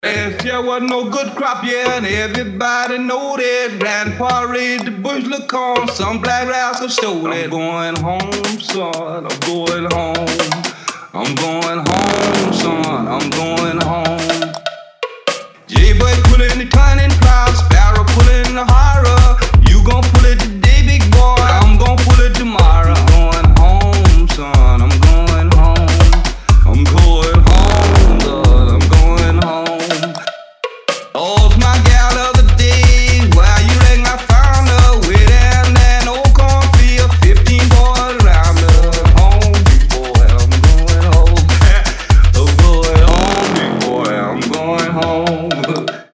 • Качество: 320, Stereo
ритмичные
dance
Tech House
Funky House
Забавная house-музычка.